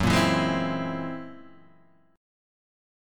F#9b5 chord {2 1 2 1 1 0} chord